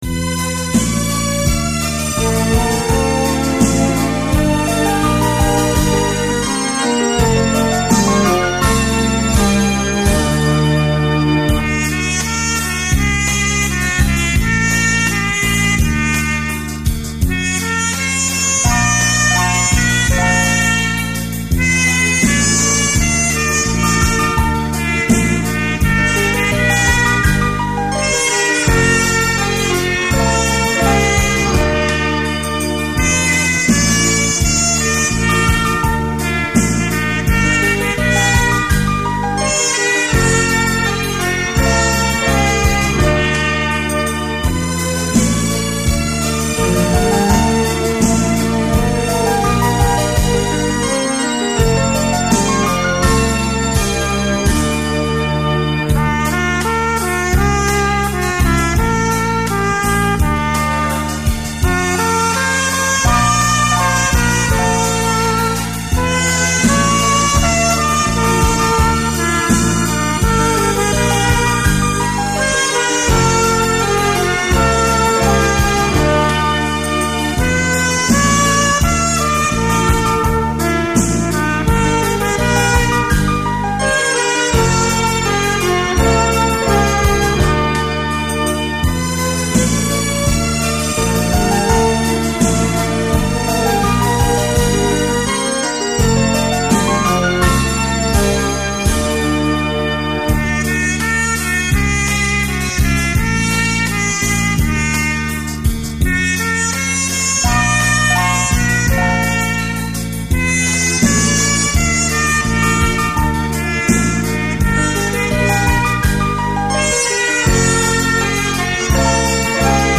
小号独奏
改编的小号音乐,曲调优美动听，十分感人。